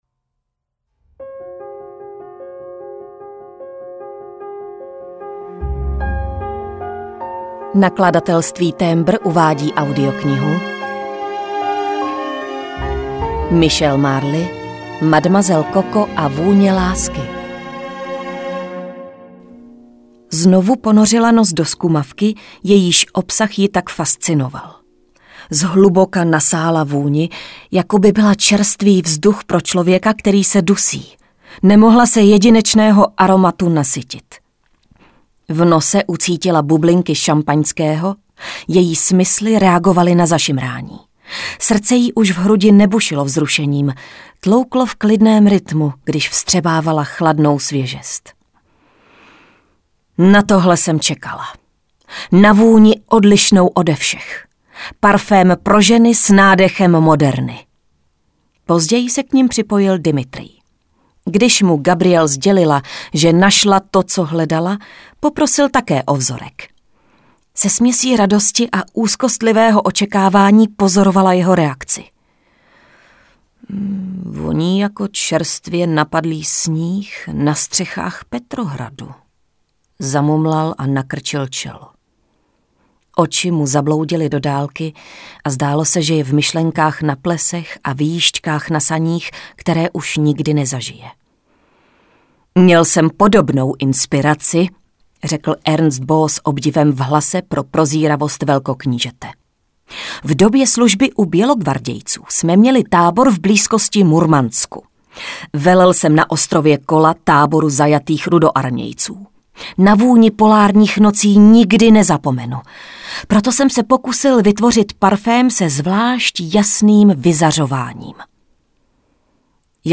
Mademoiselle Coco a vůně lásky audiokniha
Ukázka z knihy